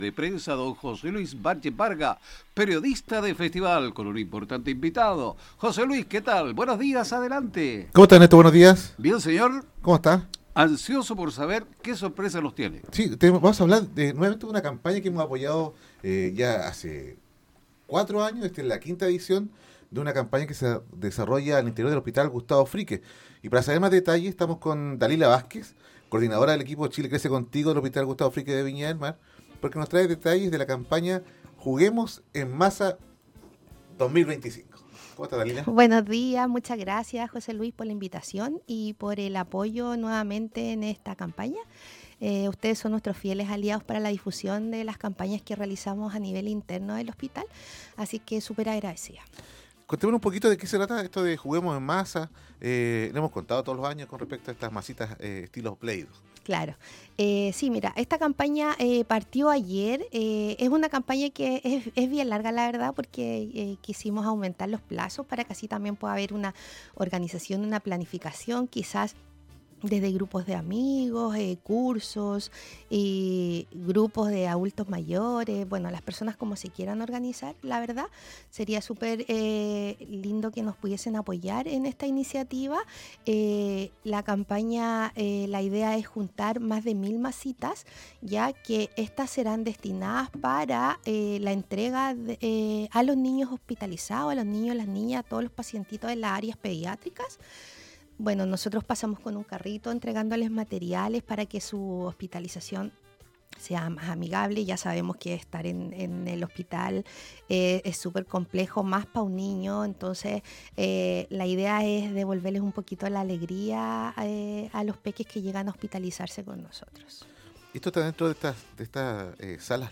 visitó los estudios en Colores